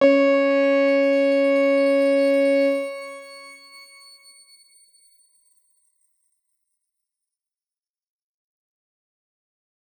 X_Grain-C#4-mf.wav